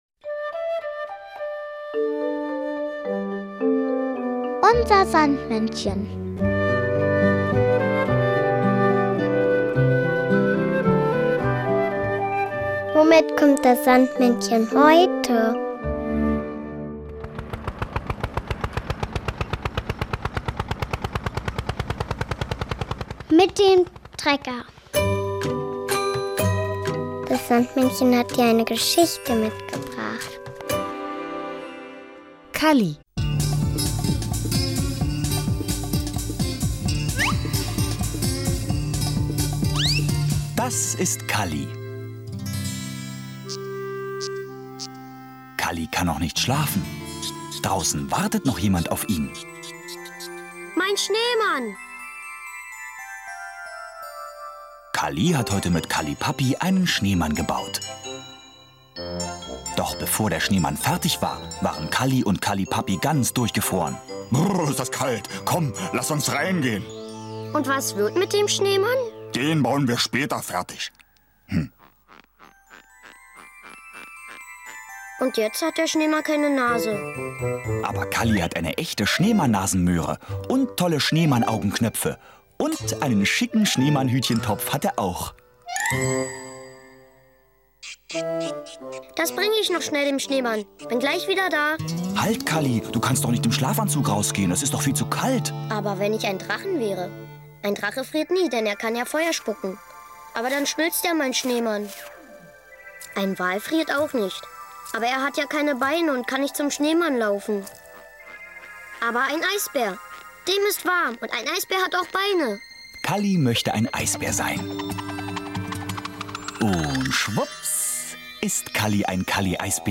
noch das Kinderlied "Tierratelied" von Ulf und Zwulf.